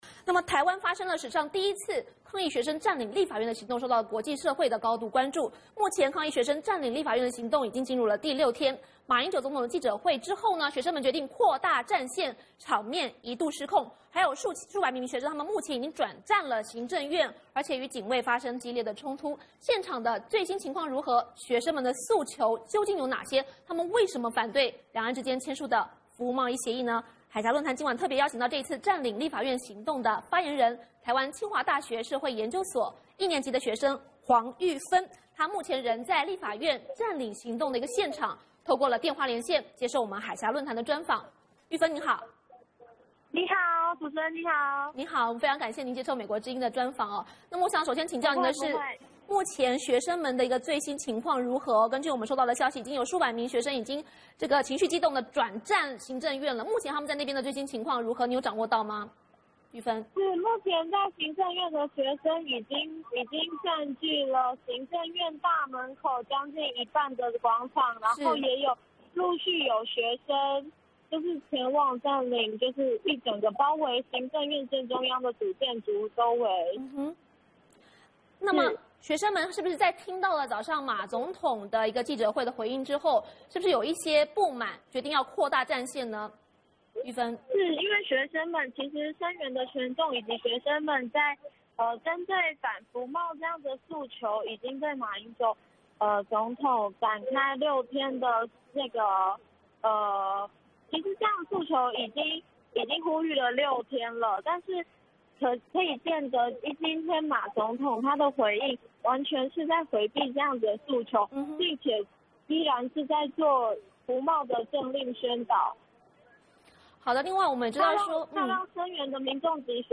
海峡论谈专访台湾占领立法院行动学生领袖 王丹发表声明